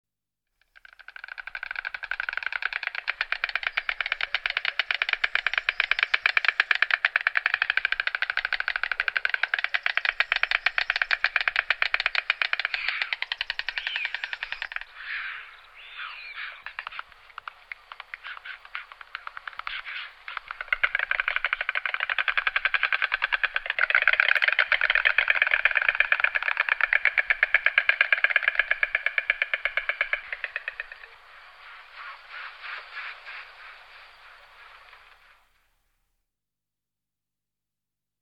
На этой странице собраны звуки аиста в разных ситуациях: крики, щелканье клювом, шум крыльев.
Аисты пощелкивают зубами